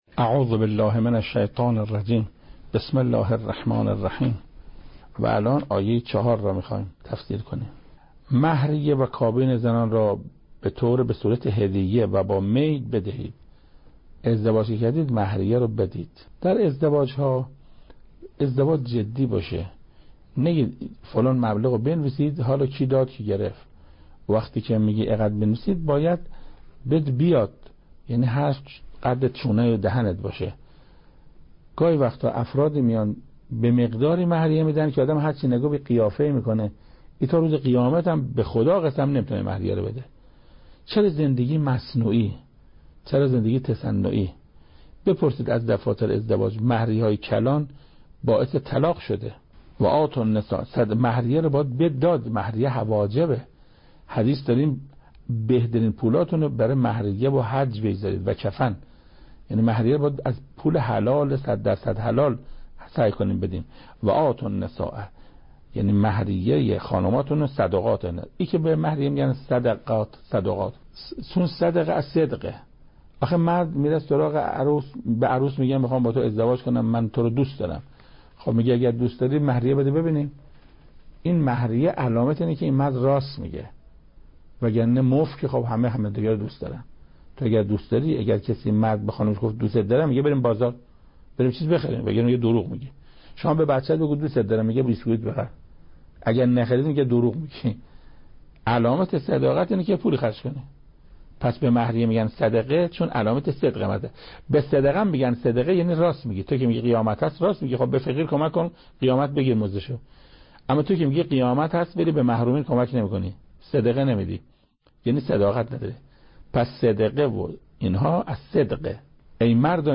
تفسیر سوره(استاد قرائتی) بخش اول